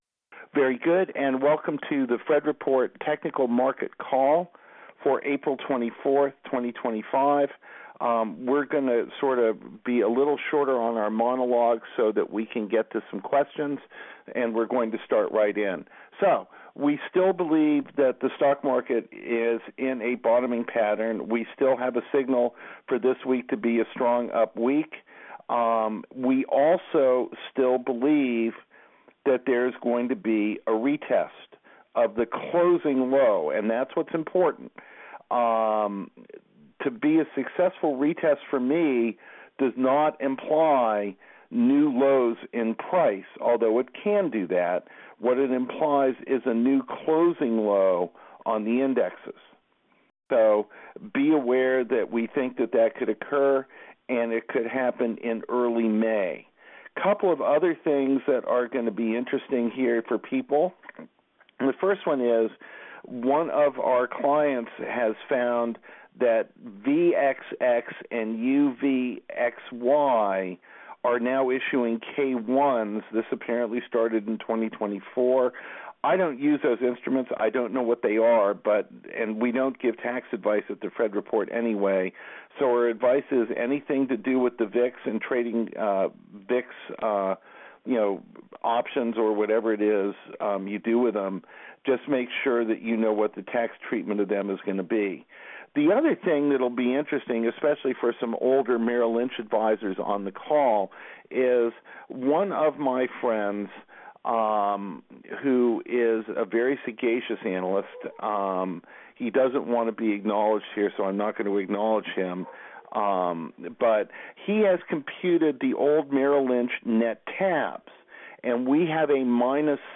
The Fred Report - Conference Call April 24, 2025